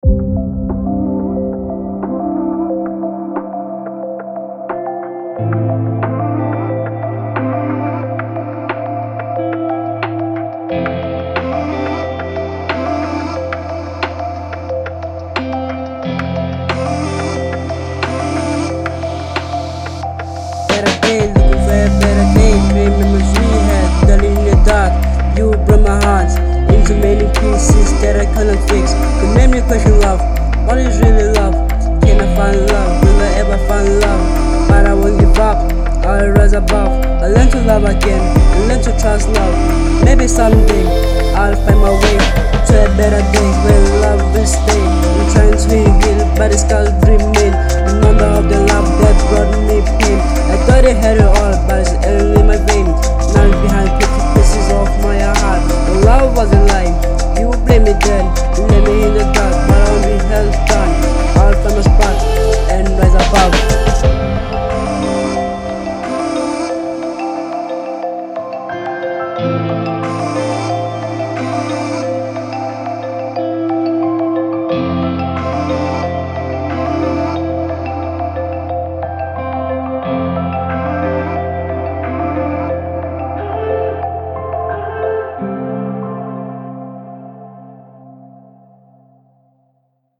01:30 Genre : Hip Hop Size